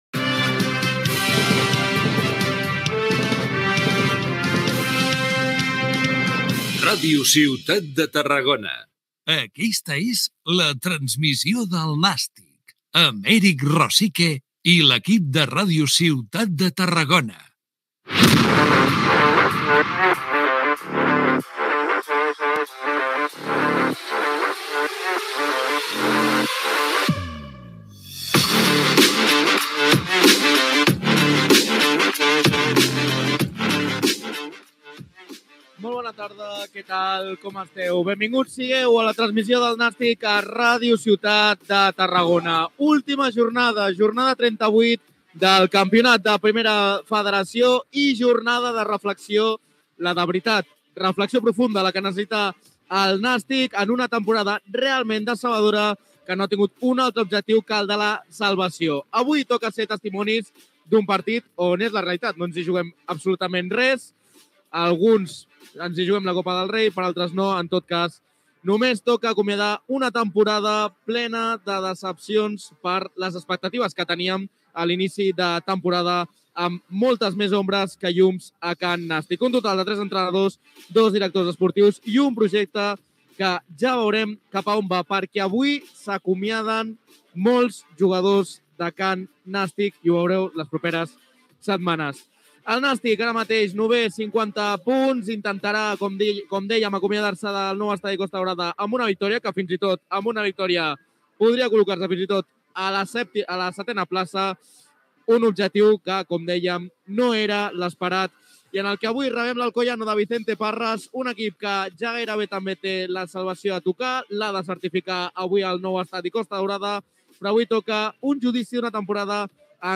Careta del programa, presentació de l'últim partit de la temporada de futbol masculí de Primera RFEF entre el Gimnàstic de Tarragona i l’Alcoyano, formes d'escoltar la transmissió, aliniacions, valoració prèvia del partit
Esportiu